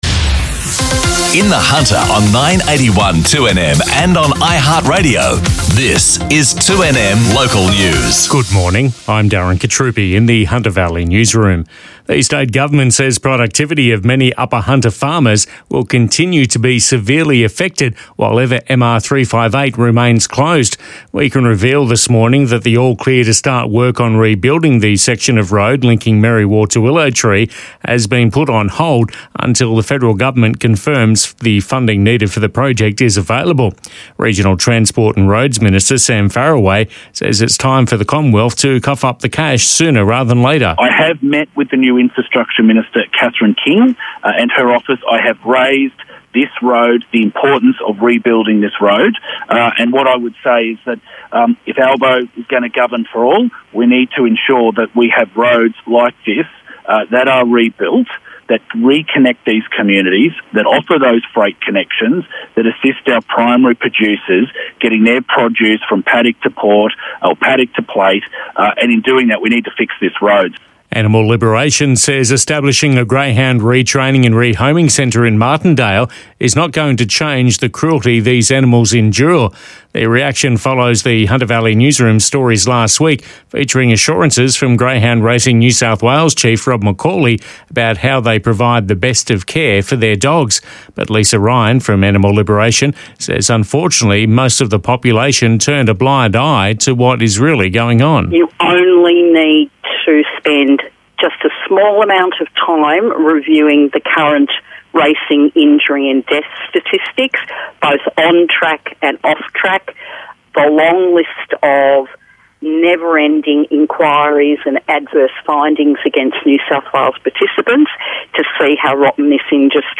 The latest Hunter Valley local news and sport.